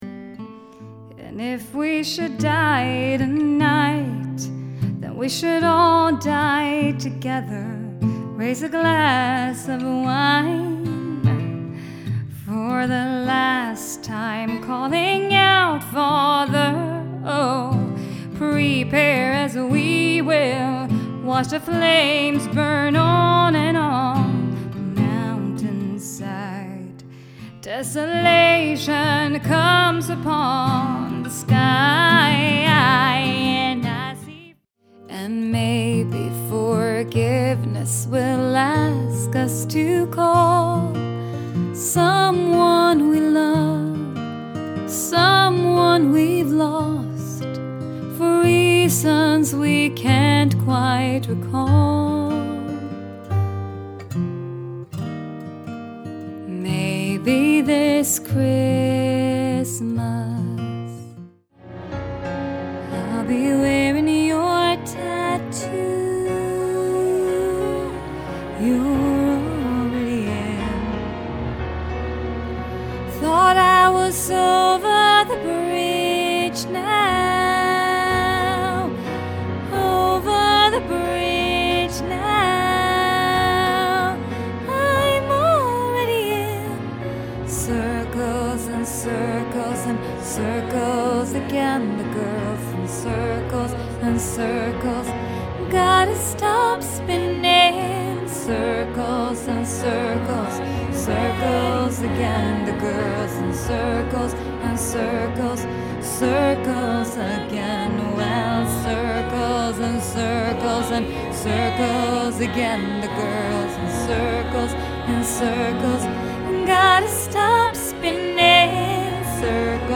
Vocalist // Composer
Vocal Reel